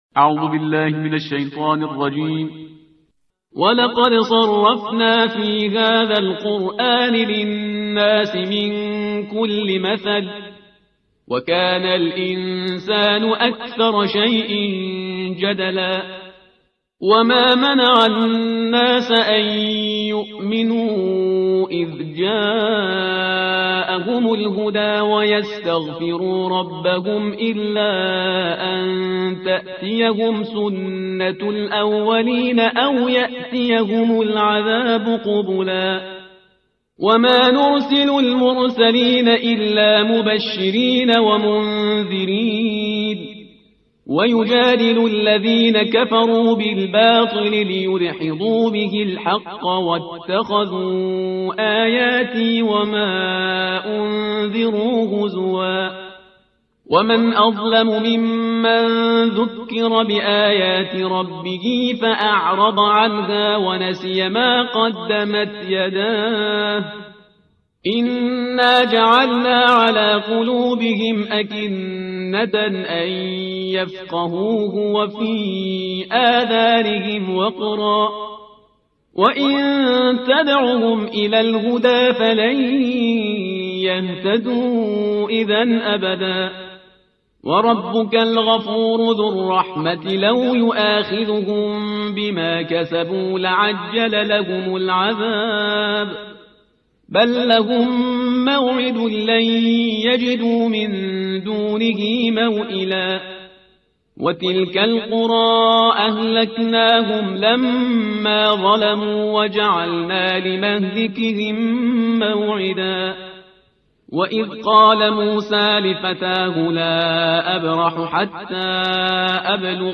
ترتیل صفحه 300 قرآن با صدای شهریار پرهیزگار